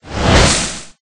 Wind1.ogg